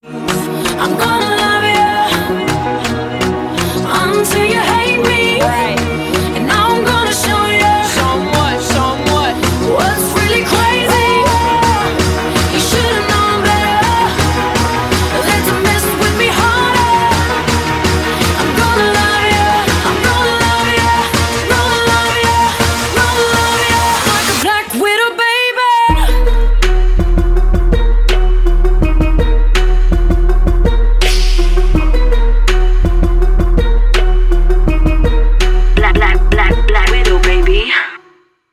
• Качество: 320, Stereo
Хип-хоп
дуэт
RnB